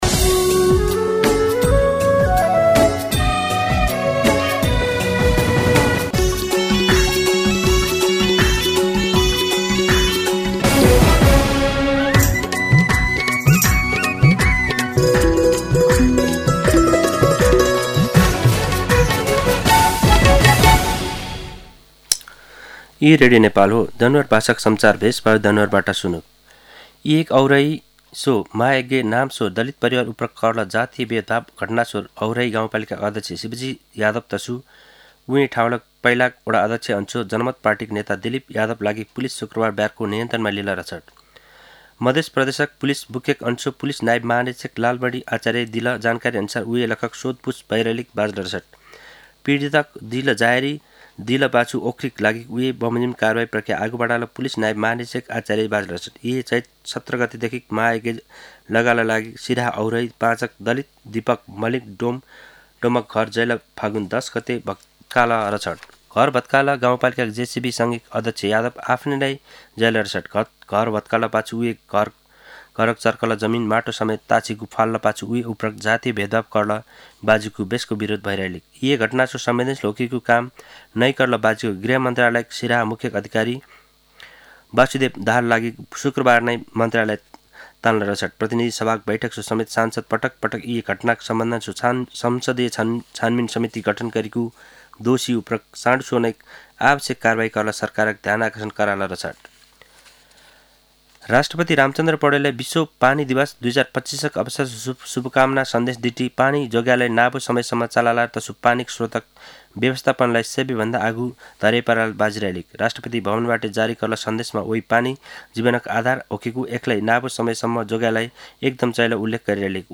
दनुवार भाषामा समाचार : ९ चैत , २०८१
Danuwar-News-12-9.mp3